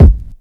Kick (3).wav